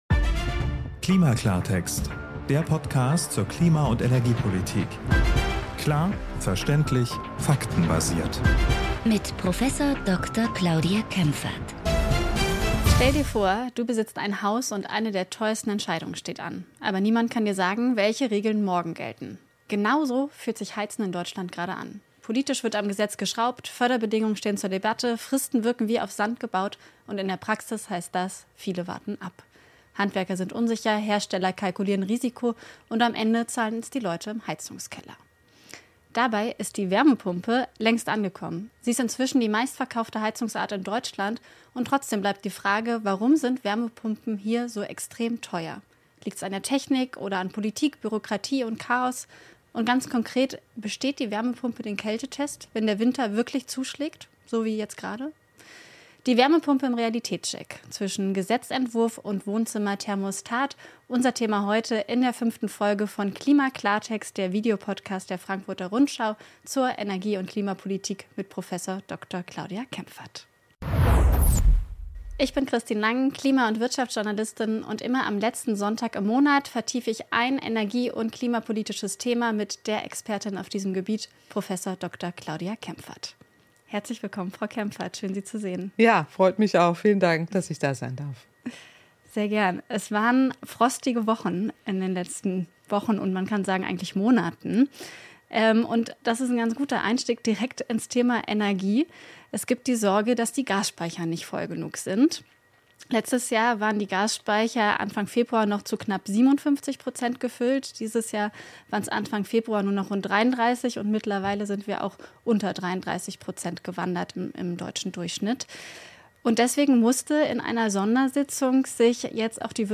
Frost-Mythos: Warum Wärmepumpen auch bei Dauerfrost funktionieren – und worauf es im Betrieb ankommt (Vorlauftemperatur, Einstellungen, hydraulischer Abgleich). O-Töne aus der Praxis: Hörer*innenberichte aus einem Frostwinter: Betrieb im (unsanierten) Altbau, Heizstab bei starkem Frost, Lautstärke und das „konstante Temperieren“ statt schnellem Hochheizen.